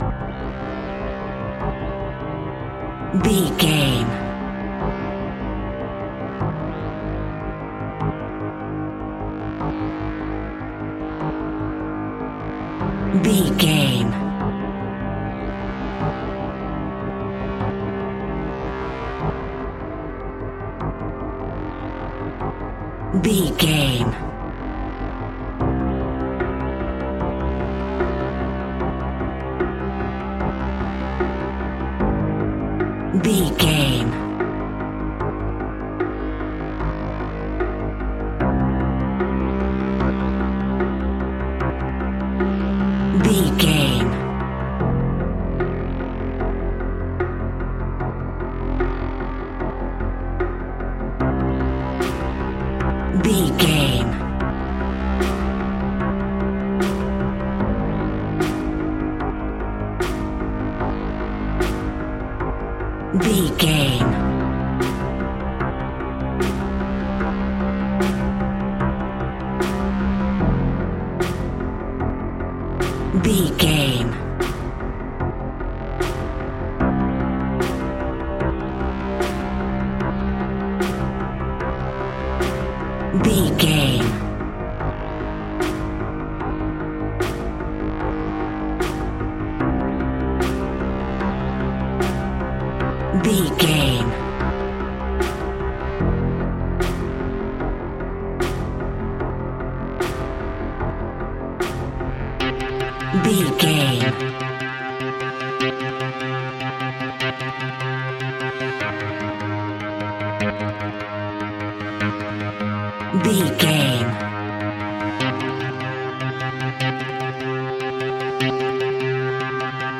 In-crescendo
Thriller
Aeolian/Minor
ominous
dark
eerie
synthesiser
percussion
horror music
Horror Pads
Horror Synths